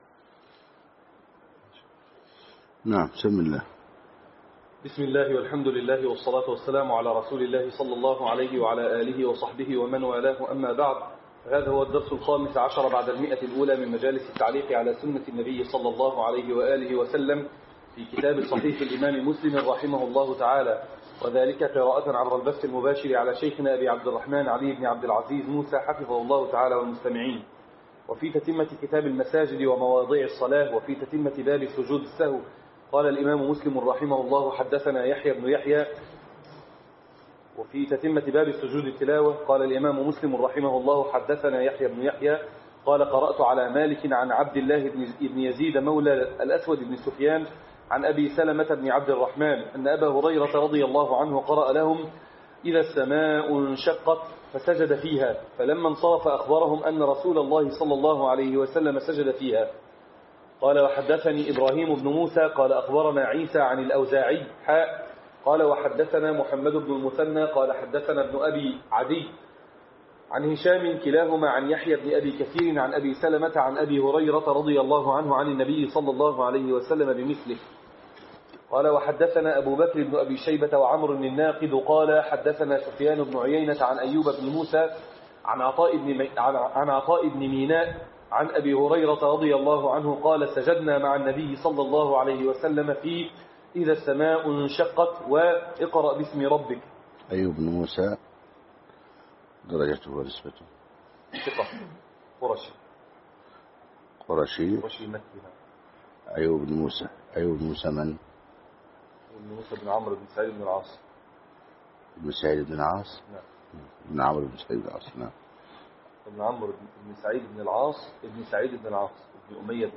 صحيح مسلم شرح